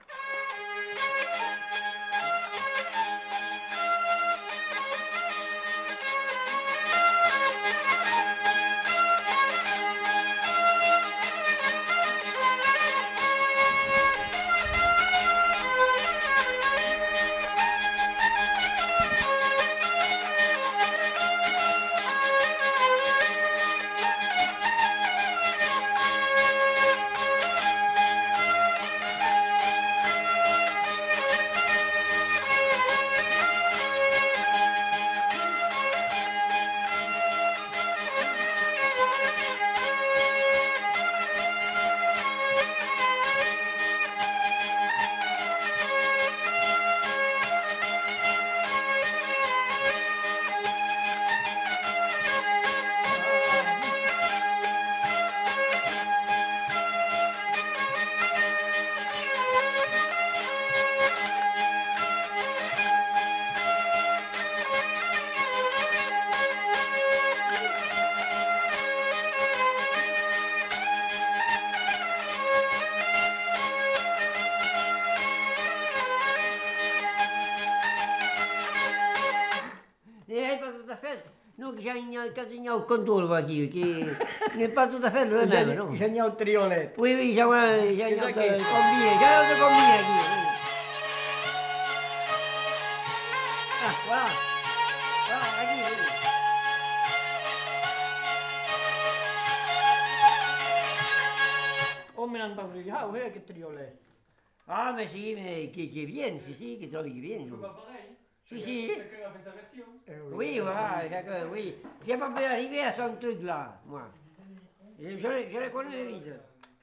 Aire culturelle : Gabardan
Lieu : Vielle-Soubiran
Genre : morceau instrumental
Instrument de musique : vielle à roue
Danse : scottish